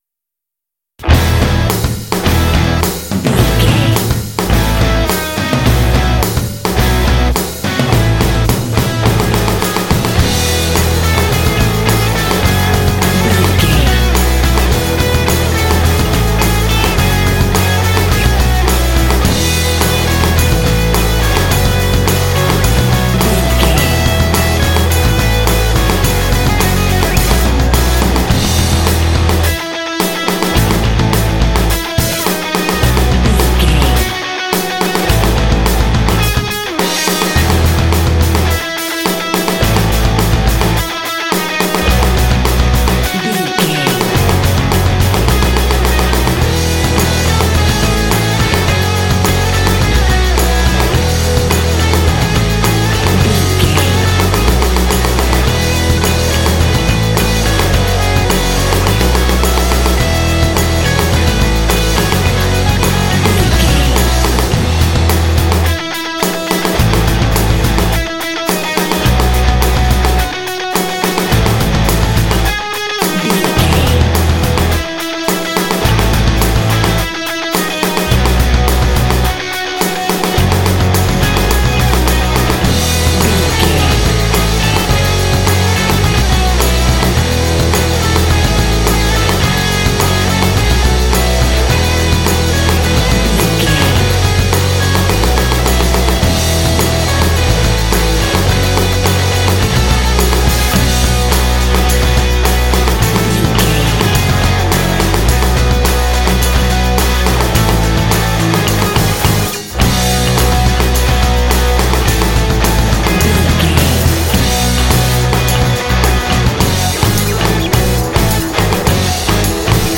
Fast paced
Ionian/Major
groovy
energetic
drums
electric guitar
bass guitar
alternative rock
classic rock